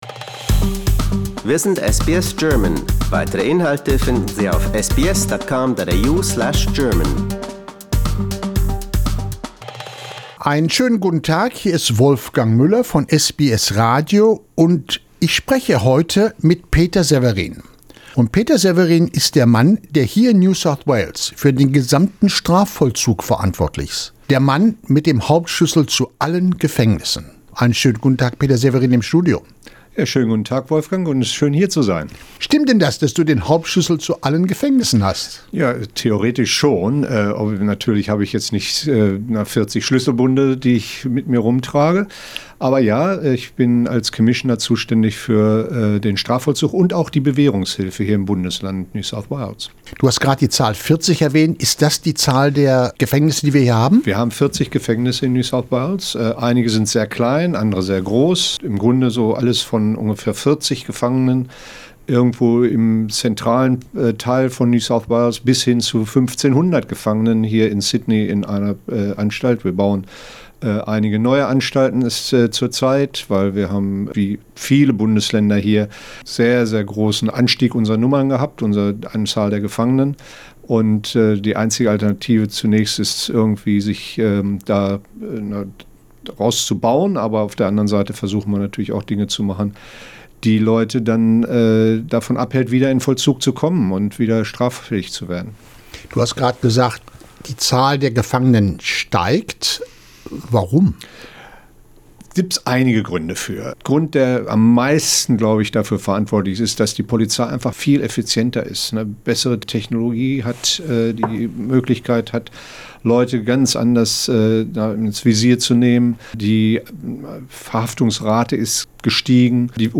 Southern Highland News Peter Severin im SBS Studio Source